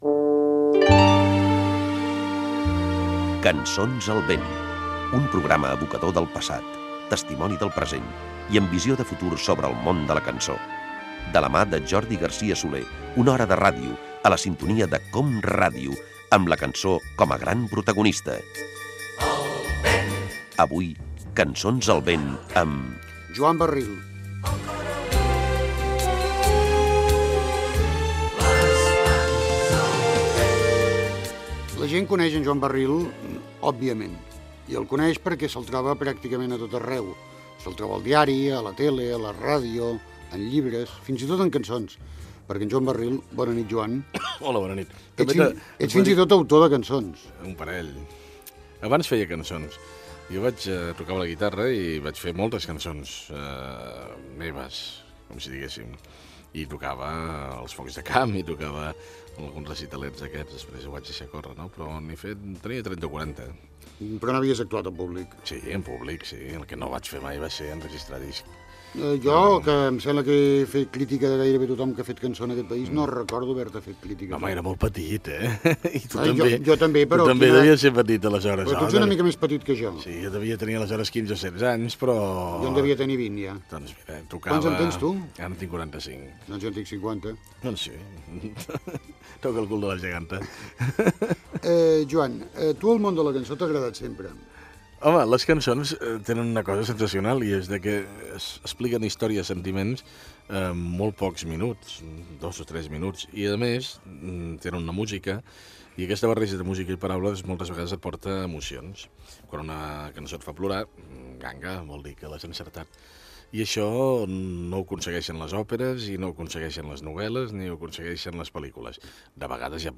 Careta del programa, presentació i fragment d'una entrevista amb la música que escoltava el periodista, l'escriptor i presentador Joan Barril.
Musical